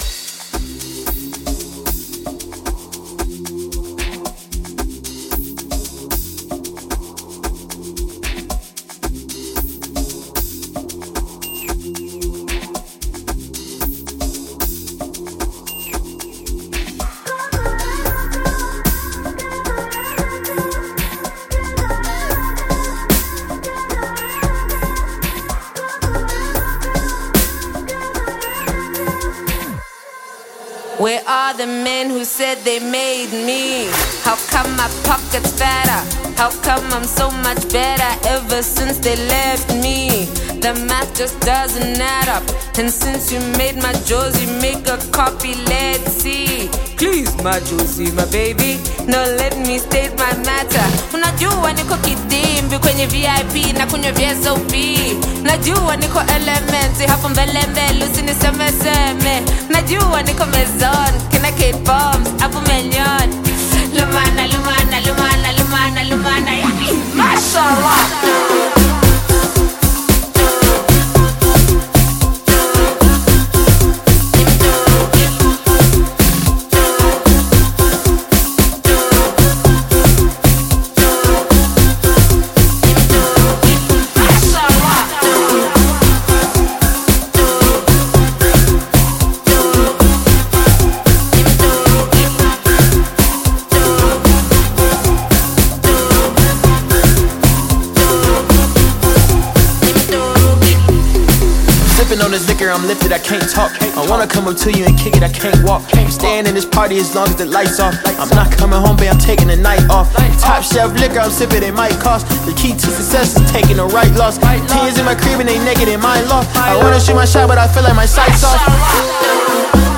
South African rapper, singer
African Music